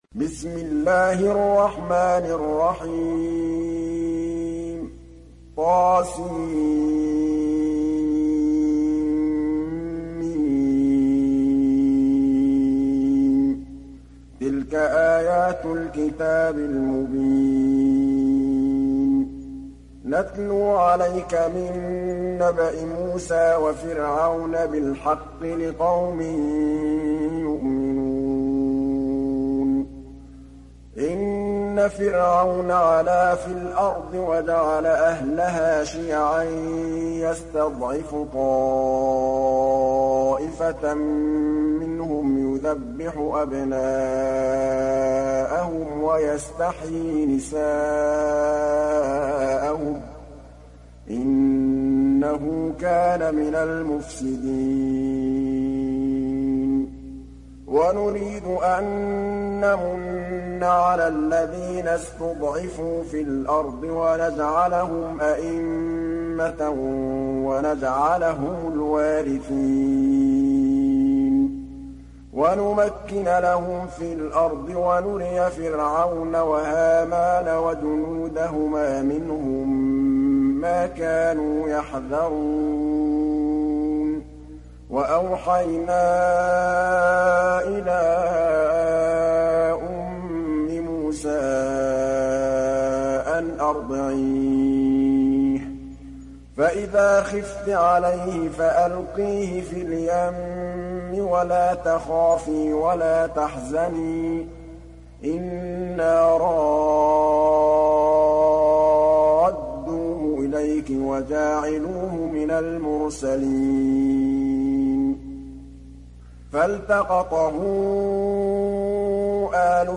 Surah Al Qasas Download mp3 Muhammad Mahmood Al Tablawi Riwayat Hafs from Asim, Download Quran and listen mp3 full direct links